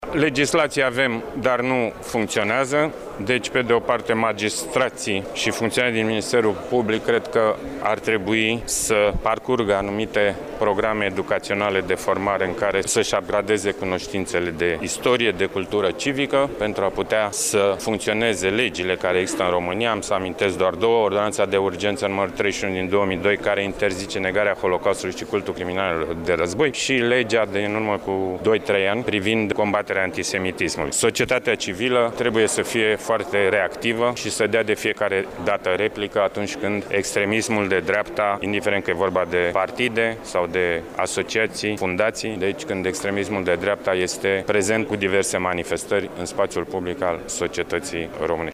Universitatea „Alexandru Ioan Cuza” din Iaşi găzduieşte în aceste două zile conferinţa internaţională cu tema „Iaşi 1941-2021: Memorie, Asumare, Uitare”.
La rândul său, directorul general al Insitutului Naţional pentru studierea Holocaustului din România, Alexandru Florian, a atras atenţia că, în ţara noastră, şi-au făcut apariţia partide de extremă dreaptă de sorginte neonazistă şi în acest context, magistraţii şi reprezentanţii ministerului public au început să devină extrem de toleranţi la aceste manifestări.